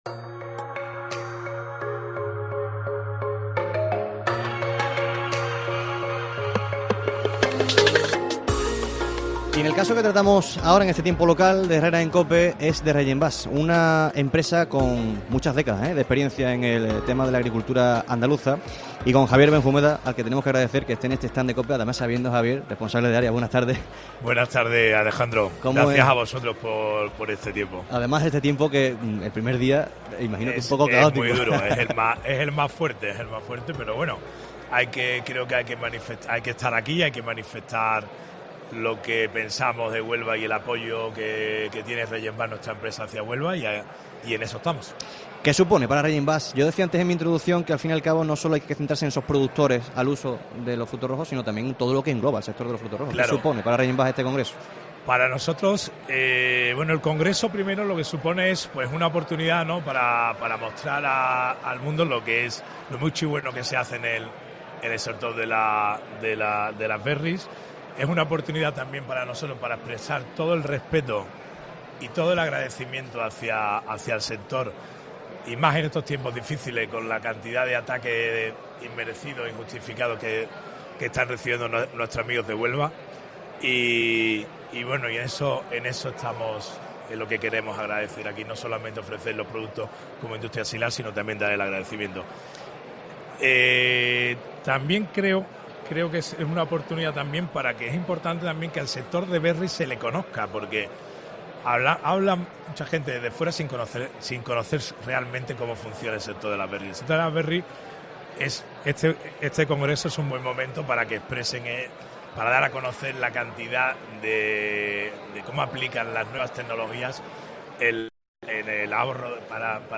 Herrera en COPE Huelva con REYENVAS desde el Congreso de los Frutos Rojos